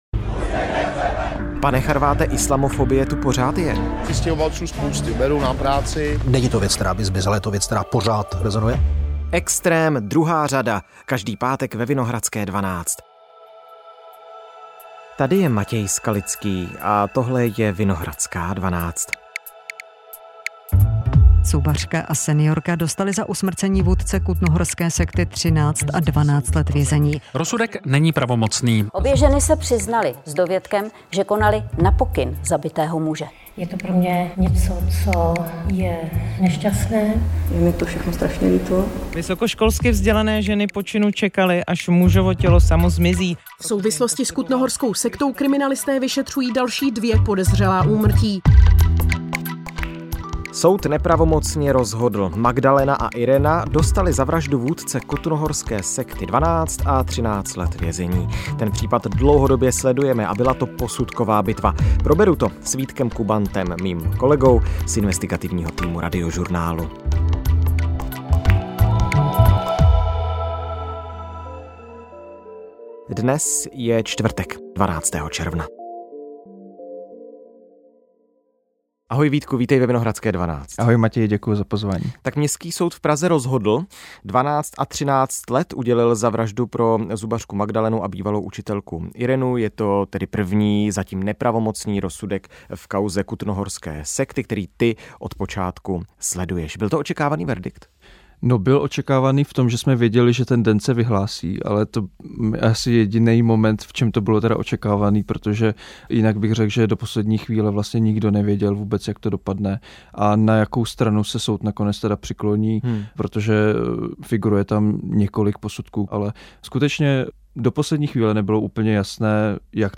Hlavní zprávy - rozhovory a komentáře: O všem, co se právě děje - 31.03.2025